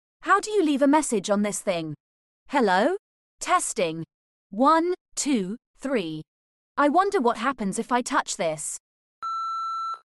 What does the standard greeting sound like?
standard-audio-greeting-eng.mp3